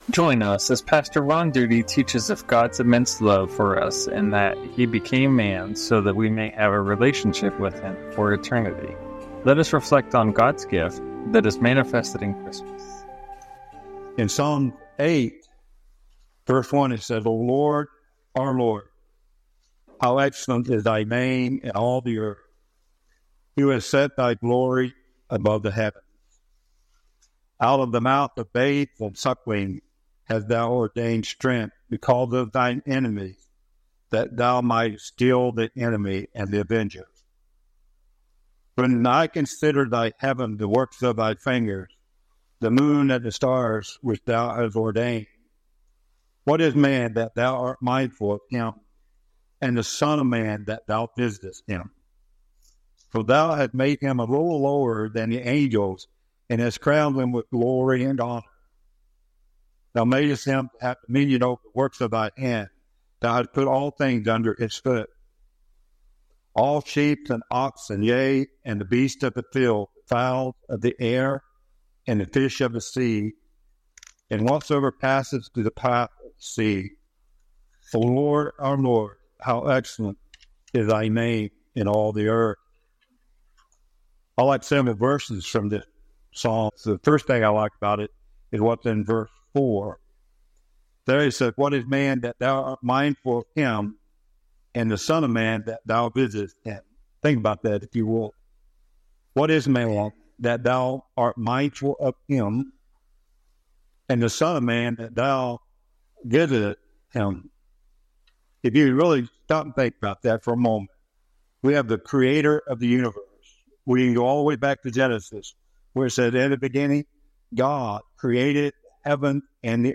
Sermons | First Baptist Church of Carroll